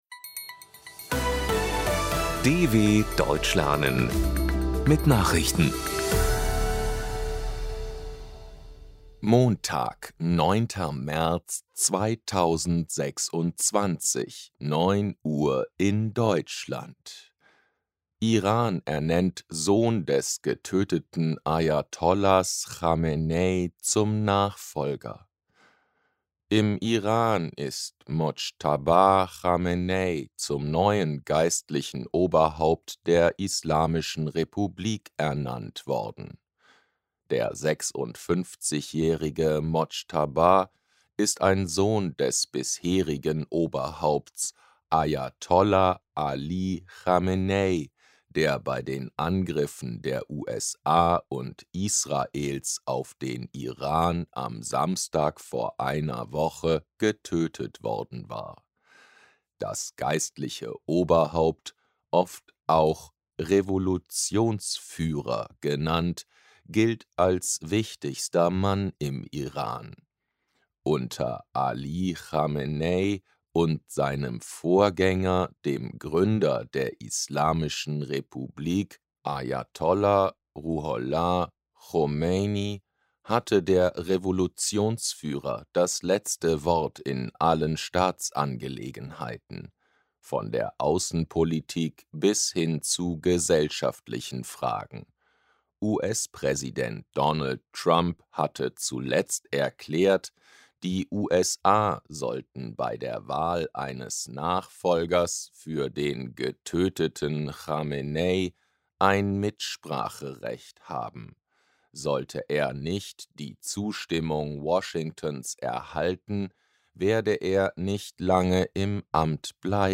09.03.2026 – Langsam Gesprochene Nachrichten
Trainiere dein Hörverstehen mit den Nachrichten der DW von Montag – als Text und als verständlich gesprochene Audio-Datei.